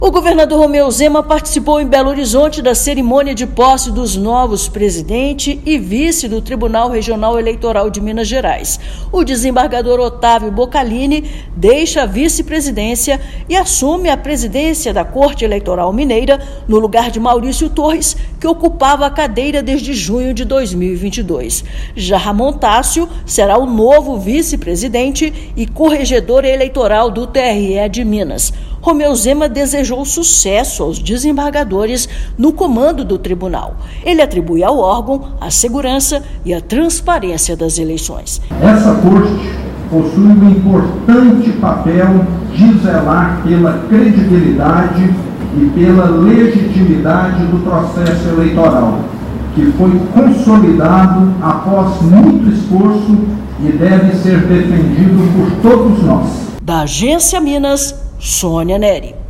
Desembargadores Octavio Boccalini e Ramom Tácio conduzirão, à frente do TRE-MG, preparativos para as eleições municipais de 2024. Ouça matéria de rádio.